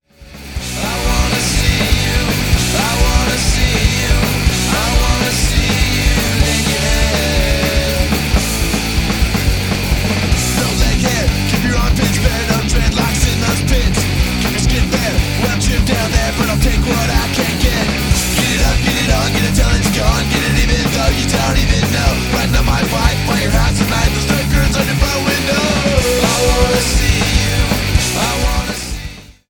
Thirteen in your face punk rock gems.